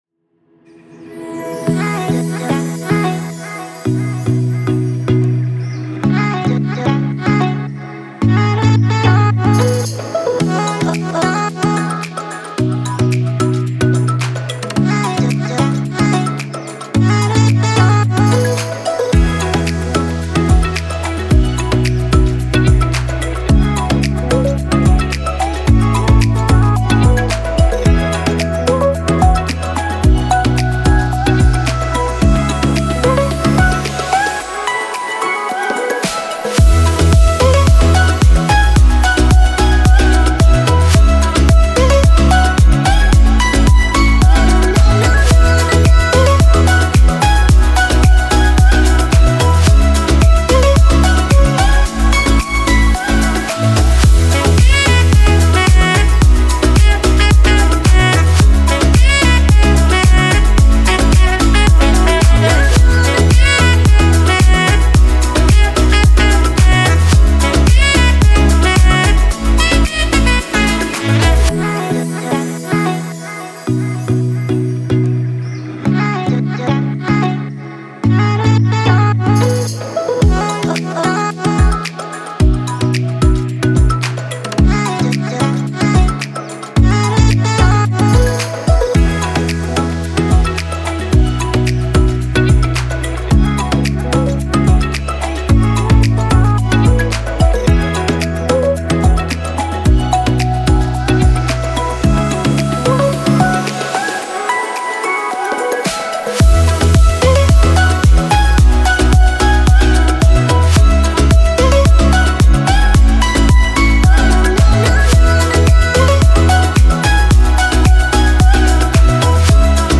موسیقی کنار تو
سبک موسیقی بی کلام